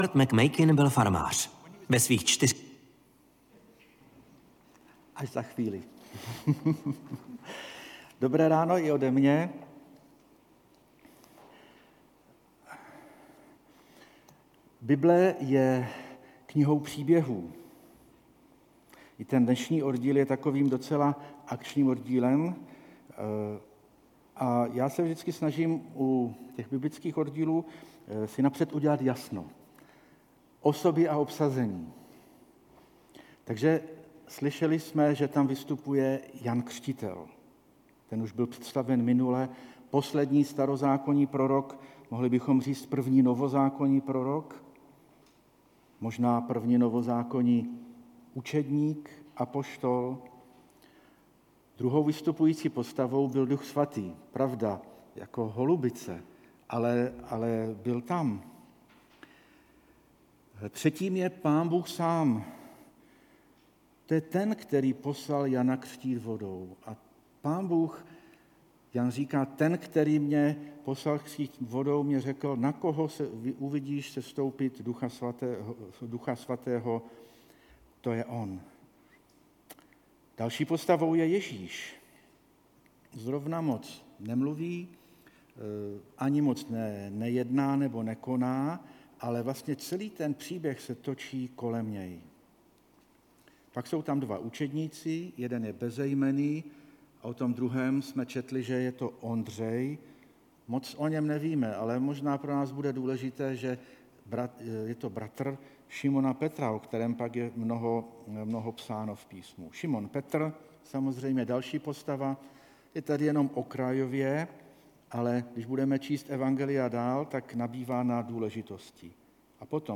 3. kázání ze série Záblesky slávy (Jan 1,29-51)
Kategorie: Nedělní bohoslužby